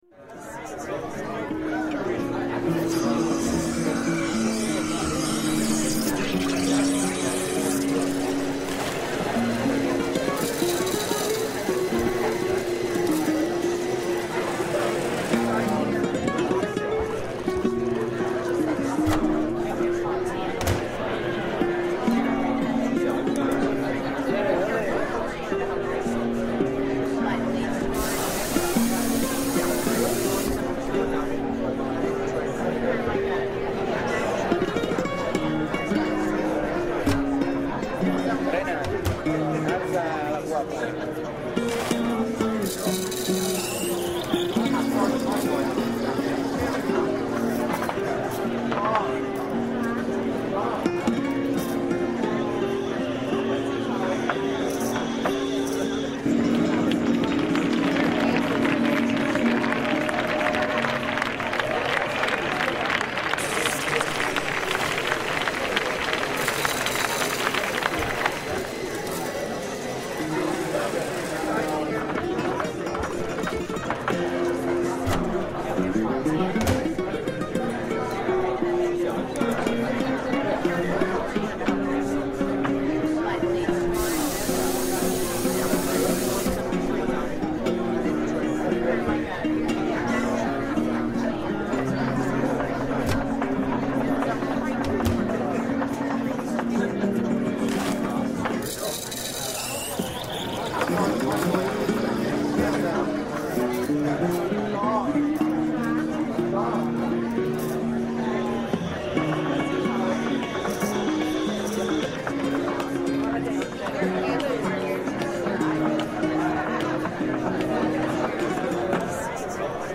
El sonido del ambiente ha sido grabado en directo...